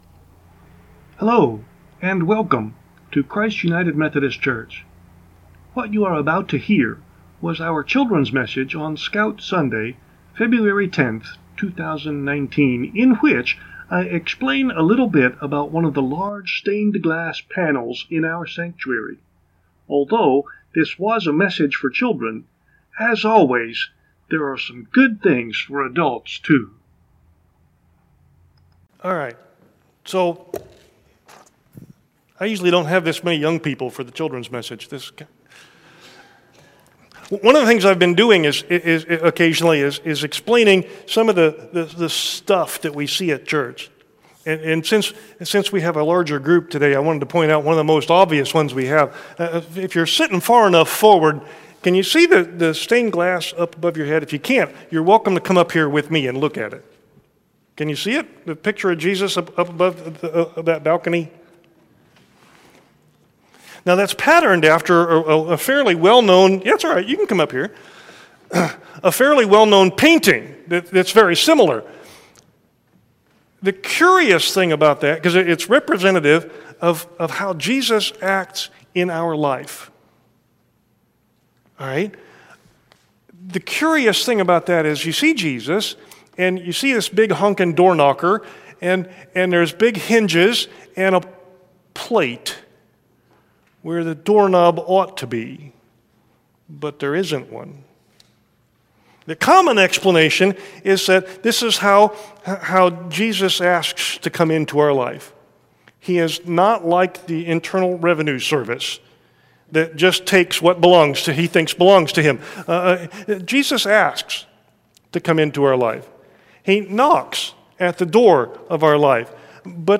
(Scout Sunday)
Although this was, technically, a children’s message, there’s a lot of good stuff here for adults too.